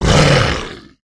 troll_commander_damage.wav